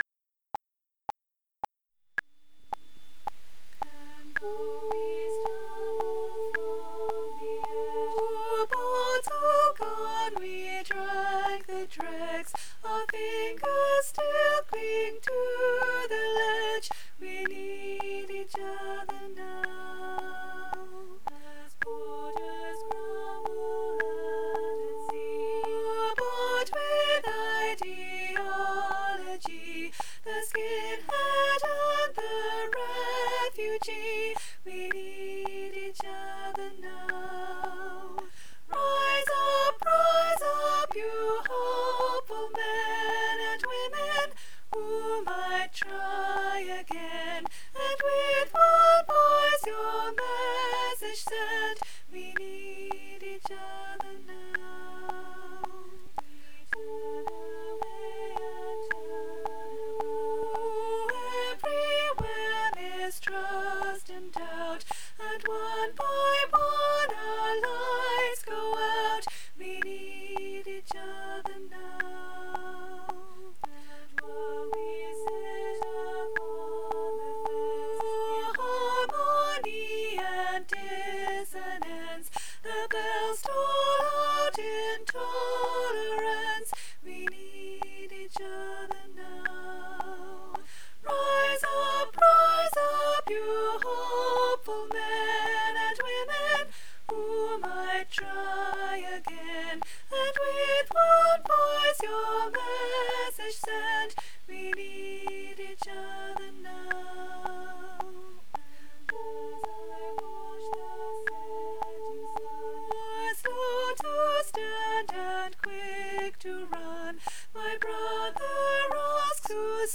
We Need Each Other Now SOP - Three Valleys Gospel Choir